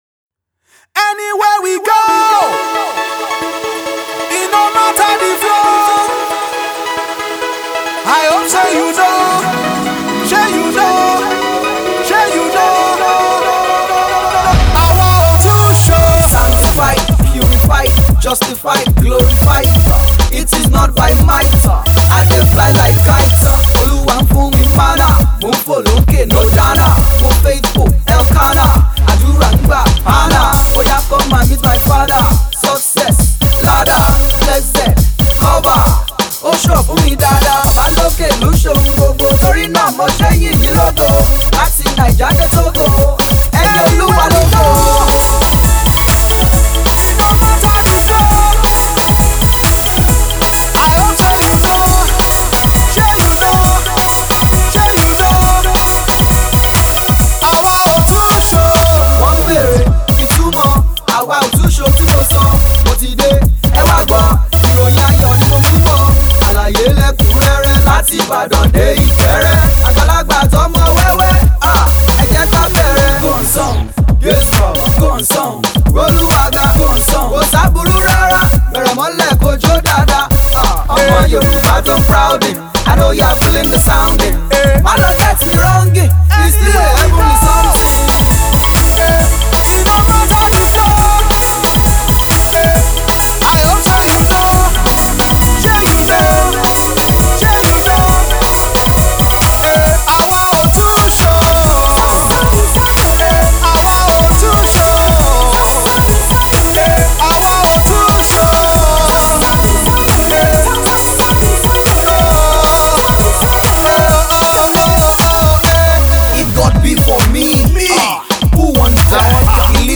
Afro-Fusion rapper/singer
mixes and matches heavy synths with lush drums
with his energetic Yoruba and Pidgin flow